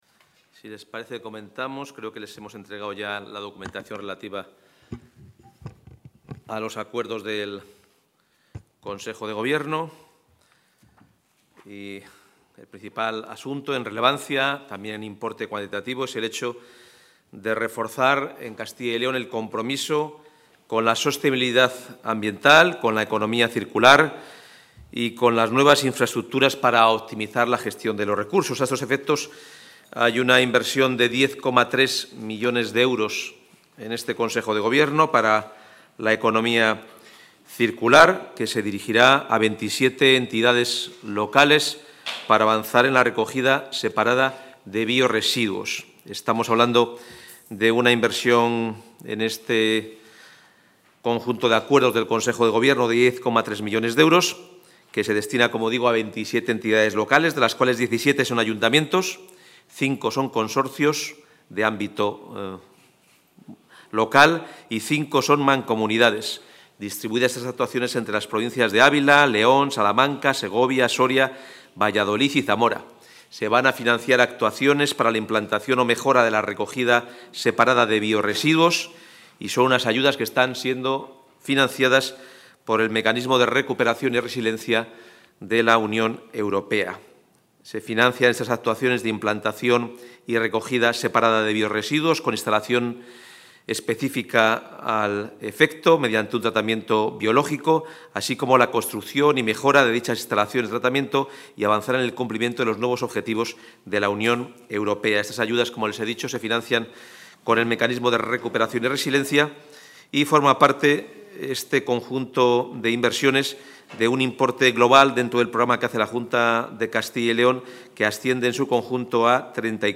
Intervención del portavoz.